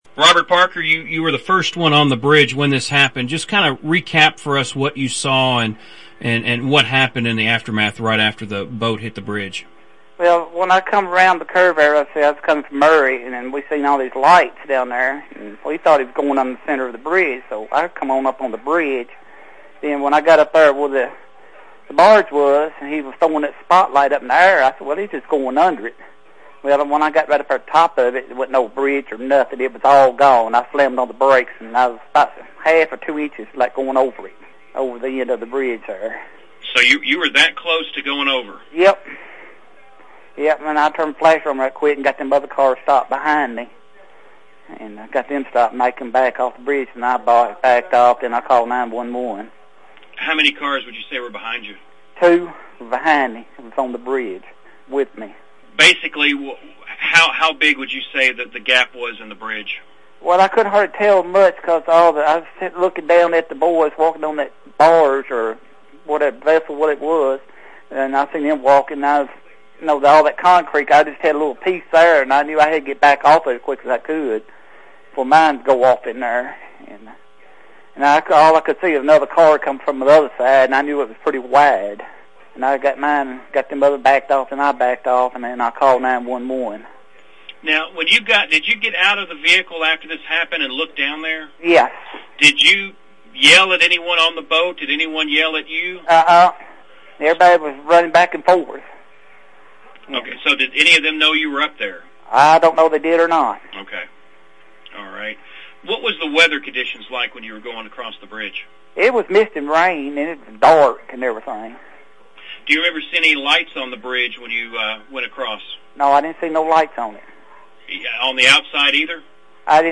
WKDZ did a great job covering the bridge hit and collapse of the Eggner’s Ferry bridge. Of all the coverage they did I want to highlight one interview. It is a classic that will live forever in the genre of country-fried disaster interviews.